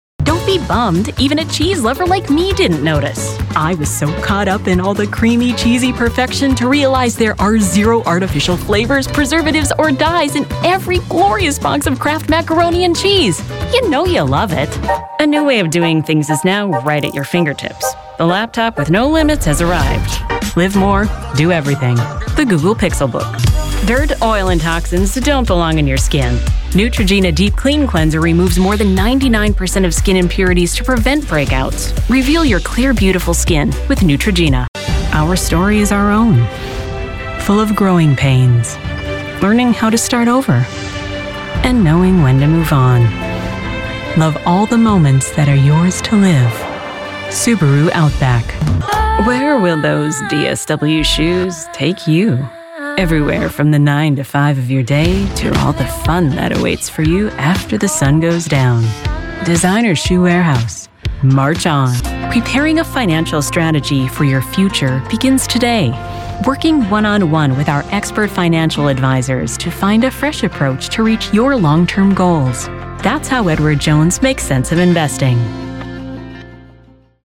Professional, friendly yet no-nonsence, educated, natural female voice for narrations, eLearning, message-on-hold, commercials, award shows and website videos
Commercial
English - Midwestern U.S. English